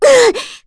FreyB-Vox_Damage_kr_02.wav